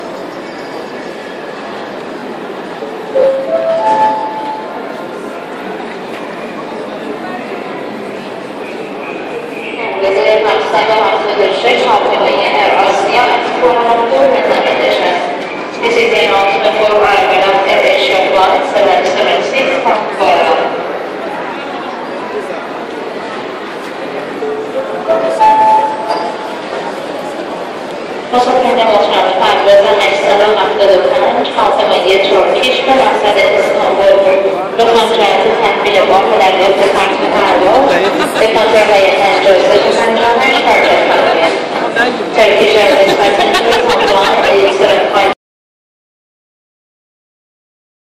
دانلود صدای فرودگاه 2 از ساعد نیوز با لینک مستقیم و کیفیت بالا
جلوه های صوتی